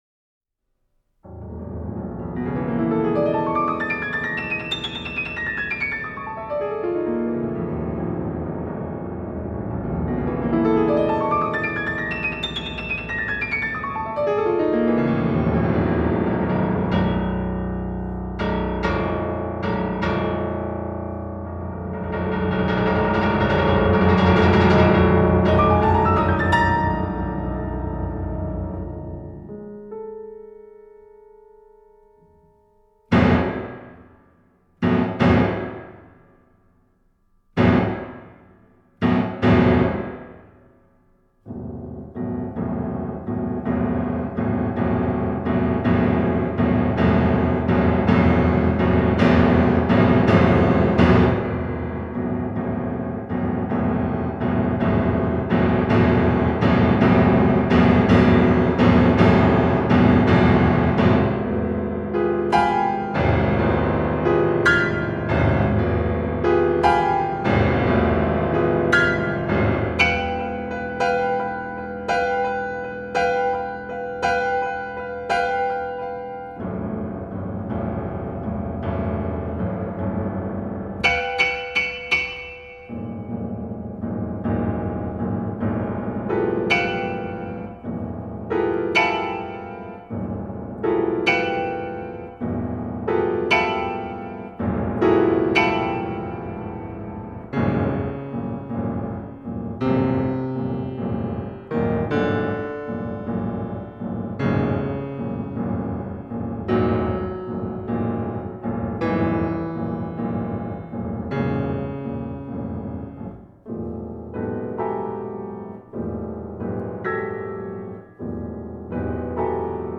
Fantasíica Araucánica para piano